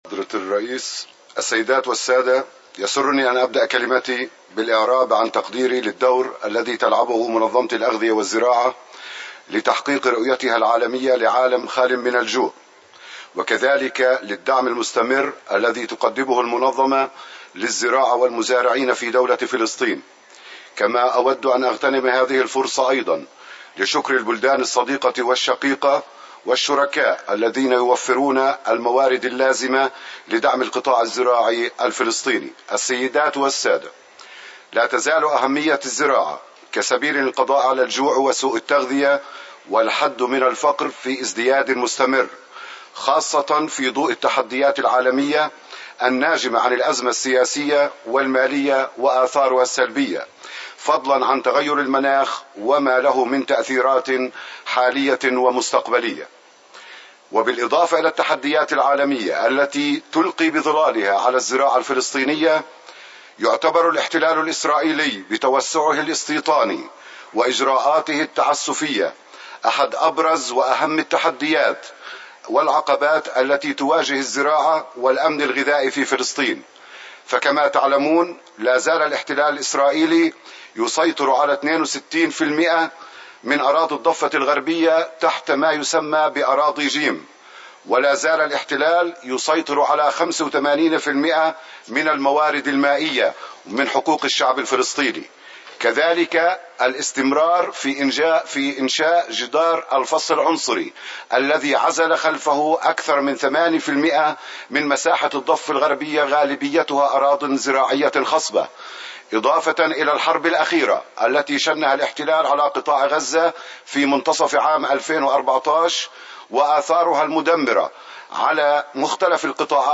Statements by Heads of Delegations under Item 9:
The statements are published as delivered and should be treated as Verbatim Records for item 9.
His Excellency Abdallah Saleh Lahlouh, Deputy Minister for Agriculture of the Ministry of Agriculture of Palestine